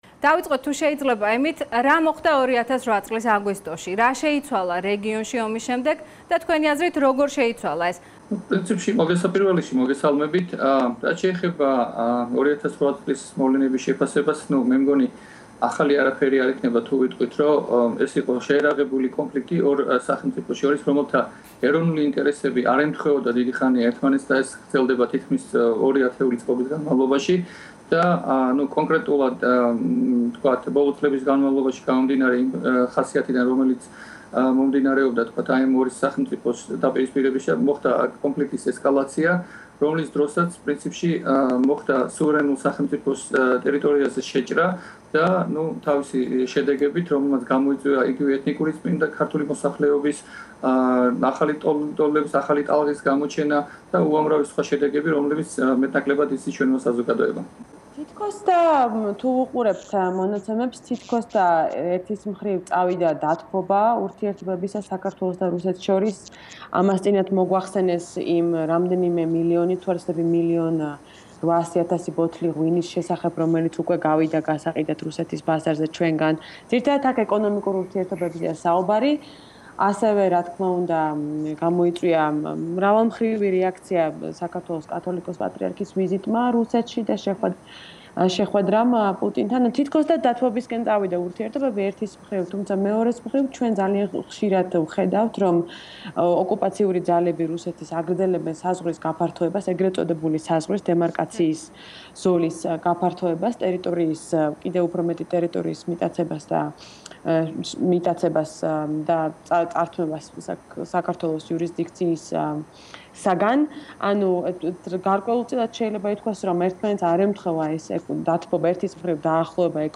panel discussion